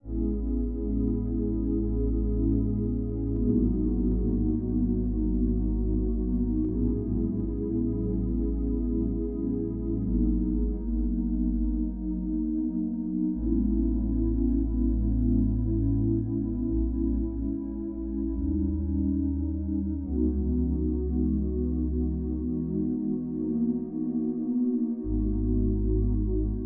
05 pad C.wav